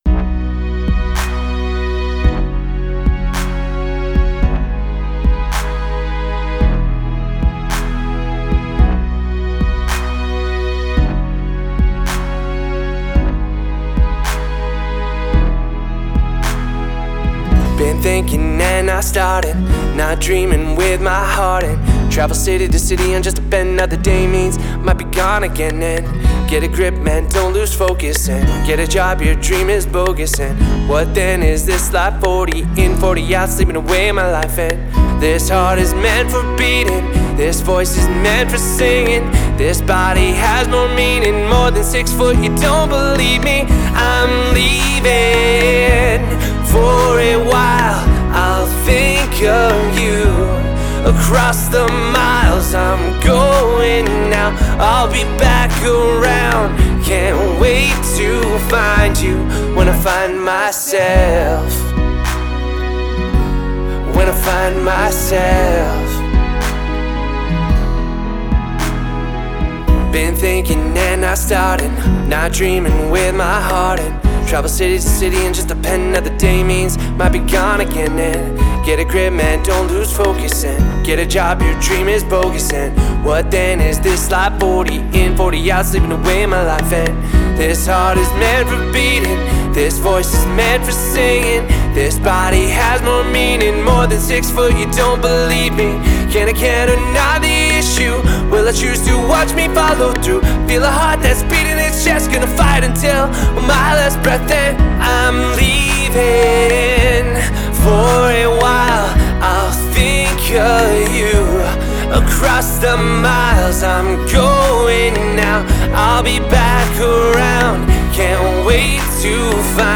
28 min of Western Canadian indie music mix